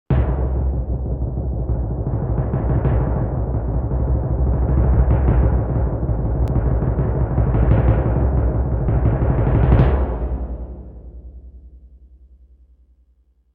Ниже звуки ударов литавры с разной частотой и силой, которые вы можете послушать онлайн и загрузить на телефон, планшет или компьютер бесплатно.
2. Барабанная дробь на литавре